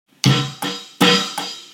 bassic drum beat